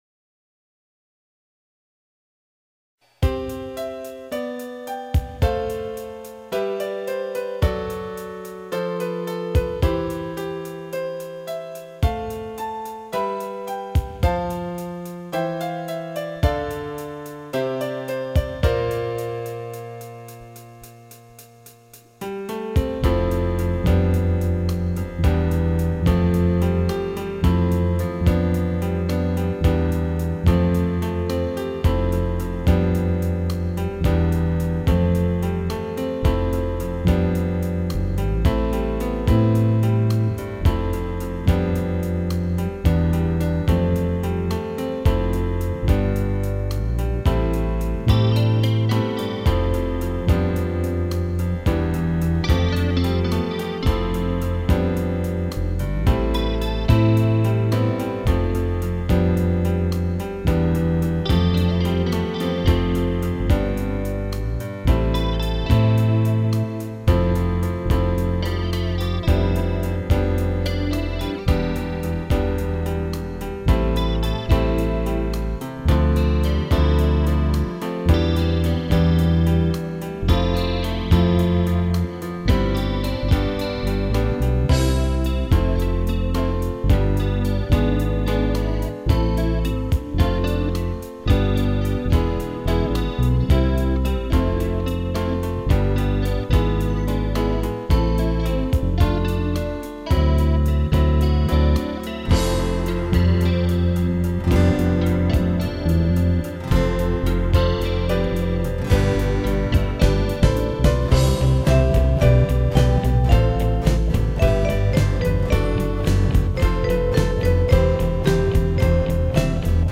TR1 Drum piano MIDI DATA
TR3 E-Giter (LEAD)
TR4 E-Giter (SIDE)
TR5 A-Giter
TR6 E-piano BELL(ビブラホン）
T-SAX
BELLの音やｓａｘの音がイマイチかな